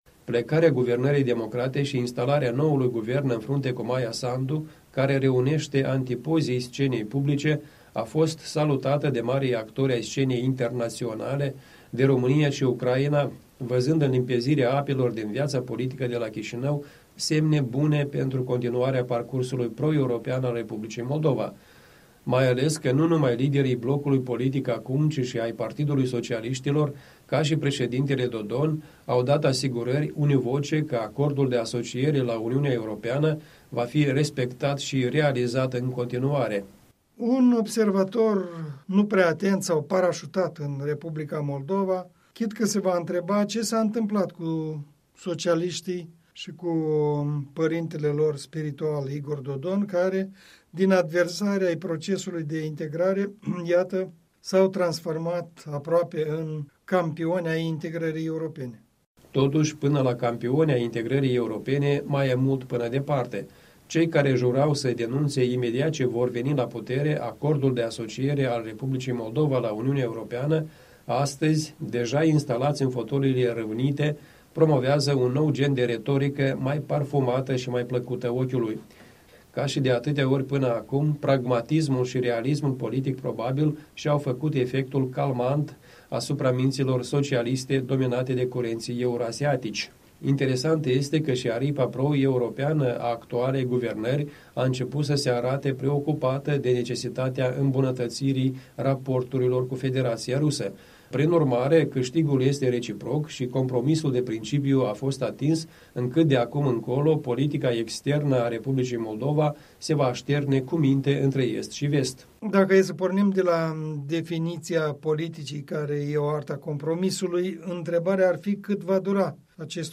Punct de vedere săptămânal în dialog.